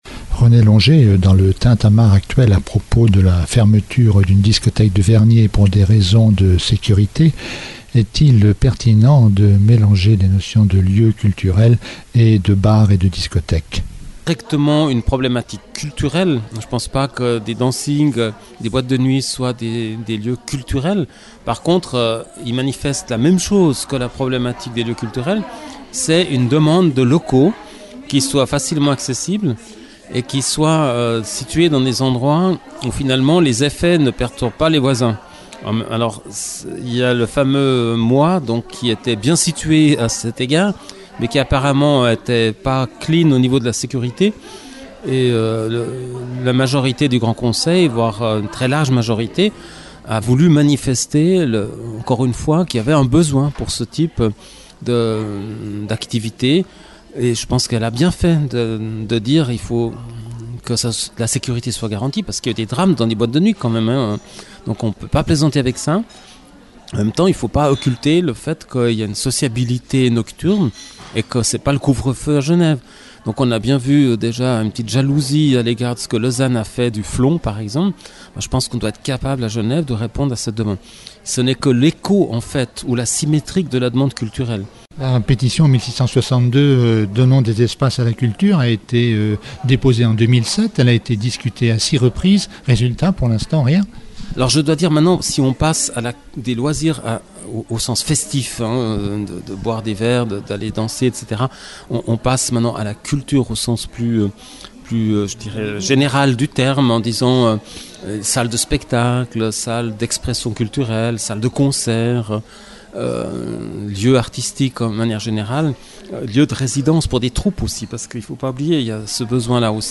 Pour René Longet, magistrat d’Onex, une fois l’inventaire des besoins achevé, l’État, qui vient de mettre en évidence son rôle culturel, jouera sa crédibilité. Entretien